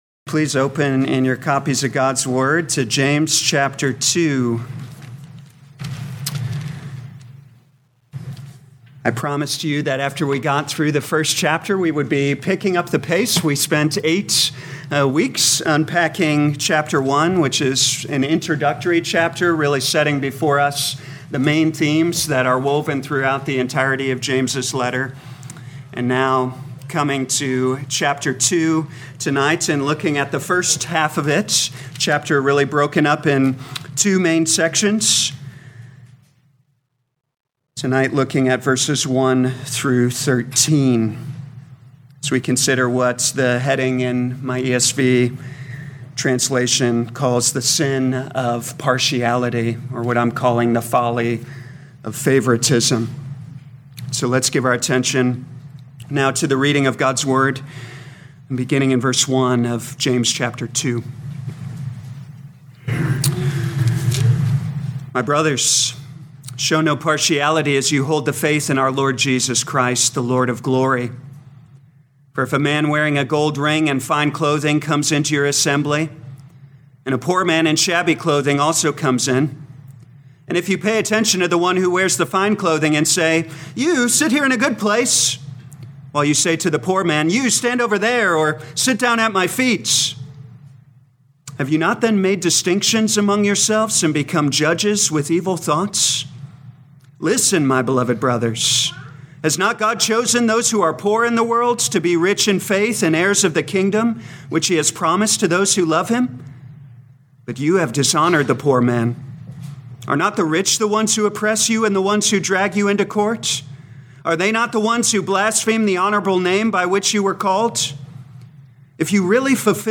2025 James Evening Service Download